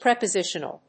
/prèpəzíʃ(ə)nəl(米国英語)/